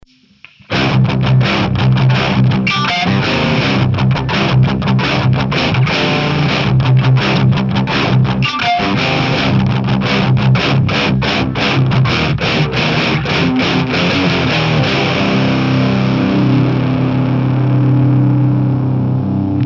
Vintage Amp Room for metall =)